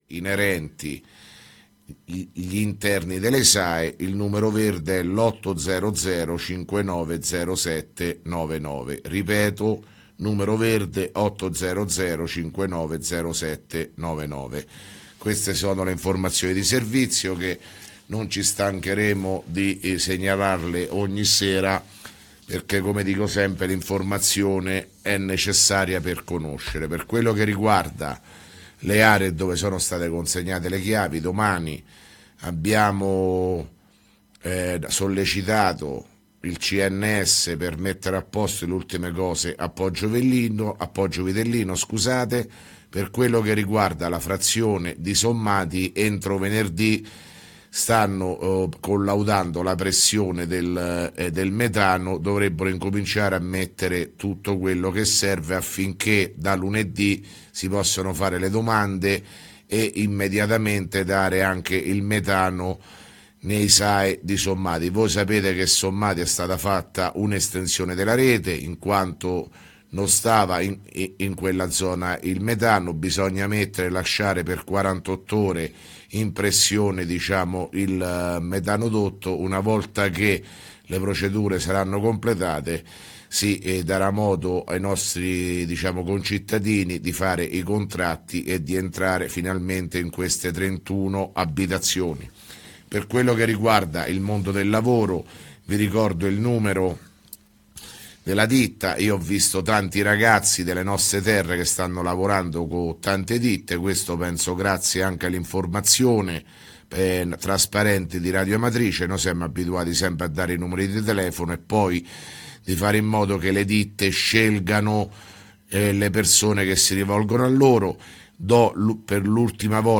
Di seguito il messaggio audio del Sindaco Sergio Pirozzi del 20 Settembre 2017